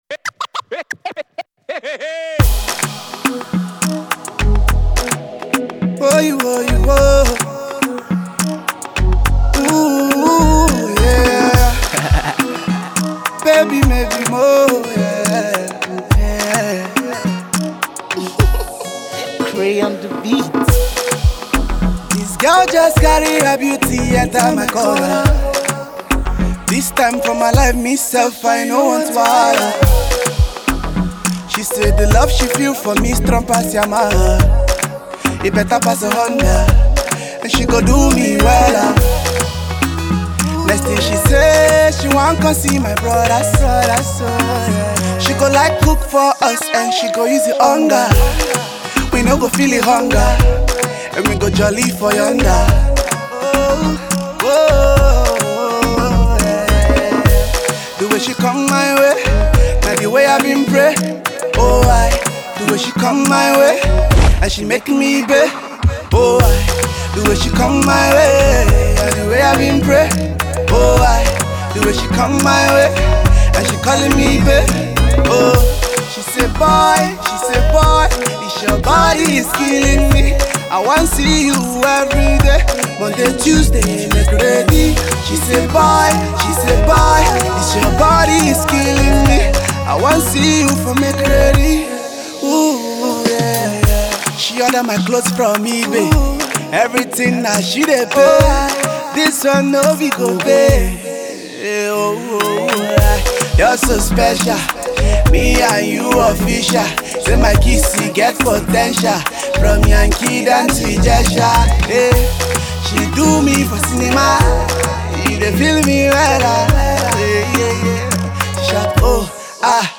a current rock solid afro-pop number